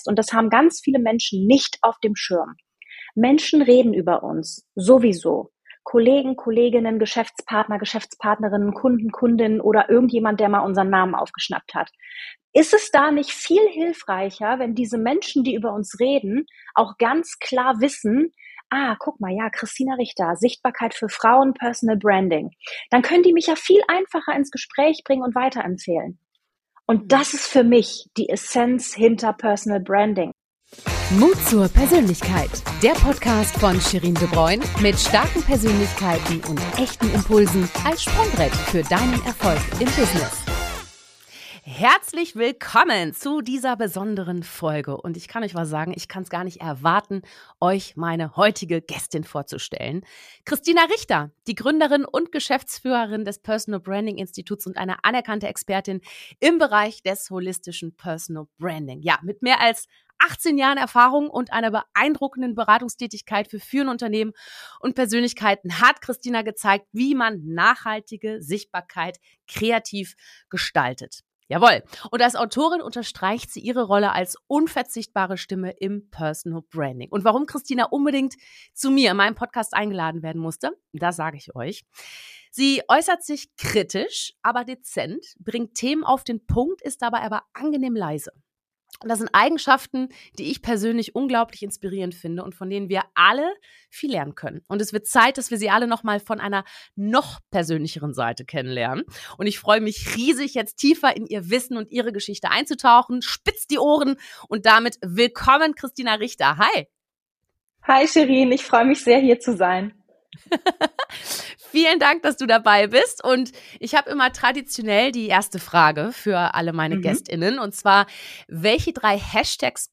Ein Gespräch voller Einsichten und Inspiration!